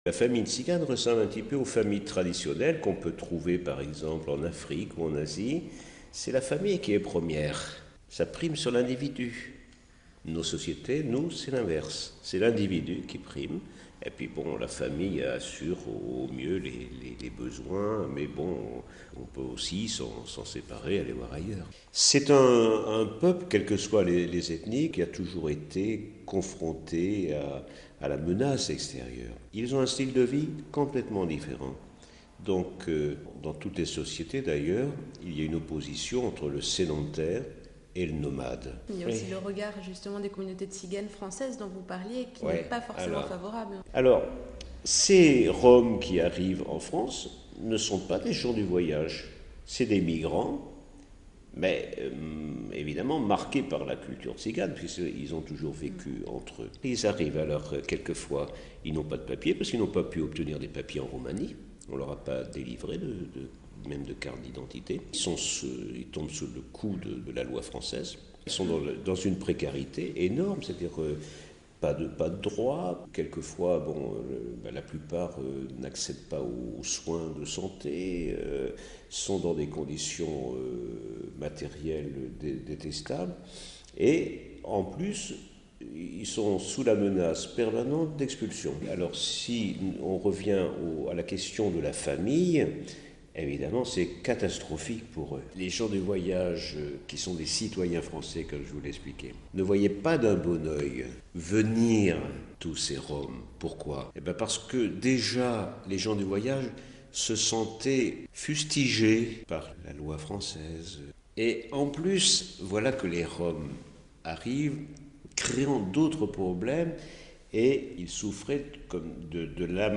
En France, Mgr Gilbert Louis, évêque de Chalons en Champagne et en charge pendant sept ans du mouvement catholique des gens du voyage, nous explique les spécificités de cette famille tzigane RealAudio